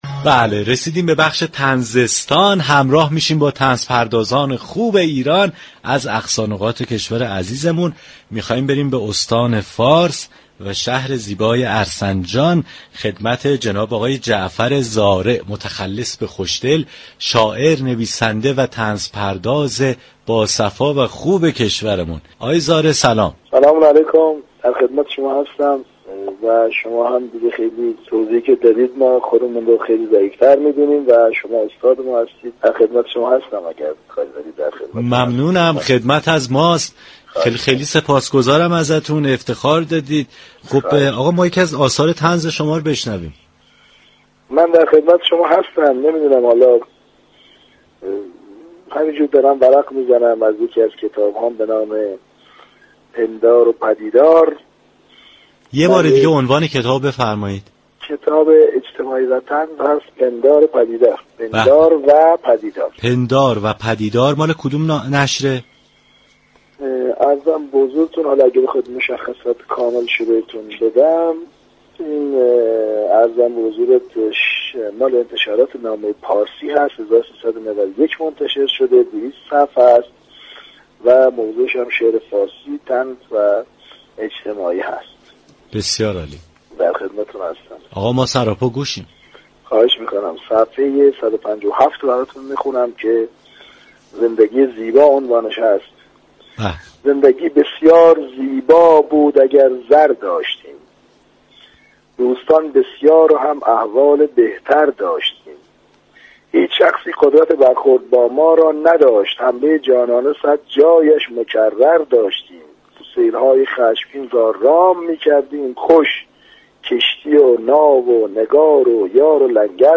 شنونده گفتگوی رادیو صبا